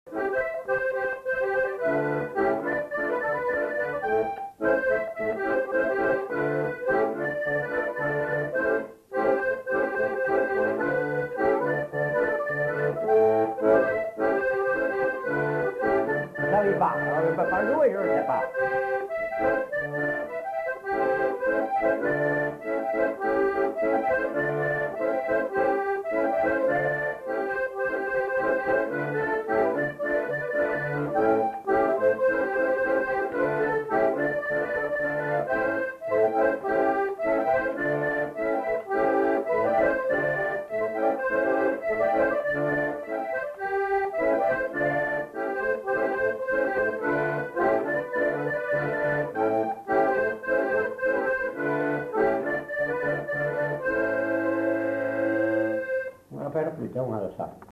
Aire culturelle : Lugues
Lieu : Pindères
Genre : morceau instrumental
Instrument de musique : accordéon diatonique
Danse : congo